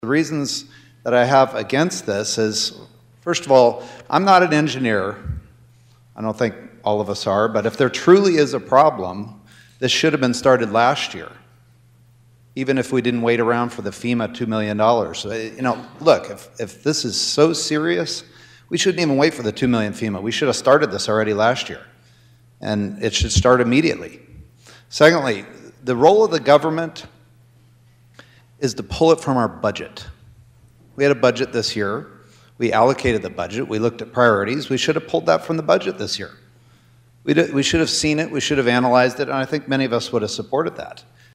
There was opposition that spoke on the floor.  Senator John Carly of Meade County doesn’t have an issue on the funding needed for the dam but was opposed to getting the money from the unclaimed properties fund.